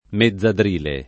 mezzadrile [ me zz adr & le ] agg.